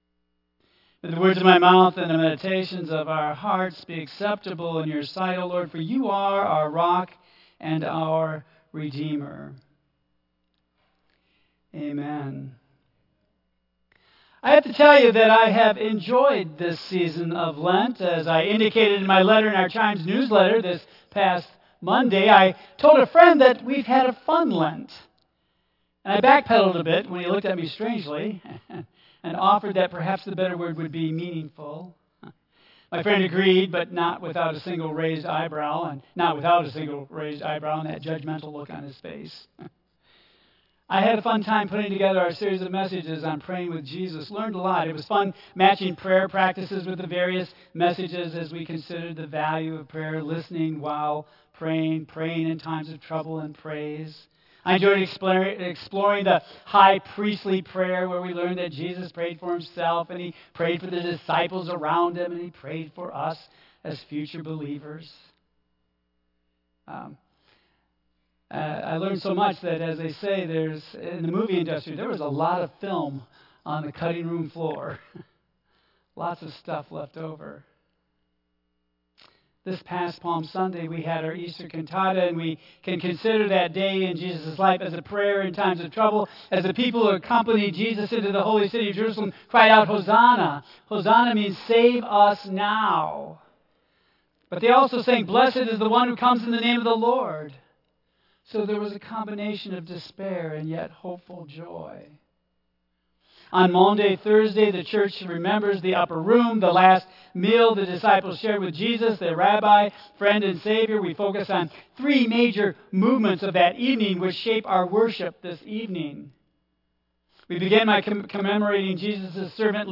Praying with Jesus Message Series Maundy Thursday Worship The message and music (hymns) will be shared throughout the service as a narration of the events of that last meal Jesus shared with his disciples.
Tagged with Lent , Michigan , Sermon , Waterford Central United Methodist Church Audio (MP3) 6 MB Previous Easter Choral Cantata Next Prayers for Forgiveness